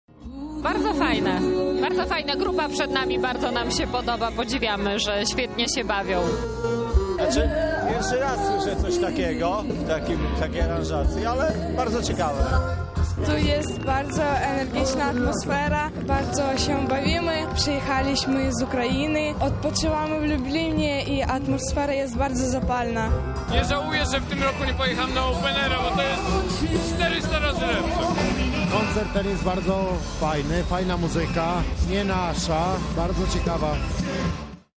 Wczoraj w ramach festiwalu Inne Brzmienia odbył się antywojenny koncert projektu Different Sounds Orchestra.
Tymon Tymański z przyjaciółmi wystąpili z pieśniami antysystemowymi i pacyfistycznymi, między innymi z repertuaru Bułata Okudżawy.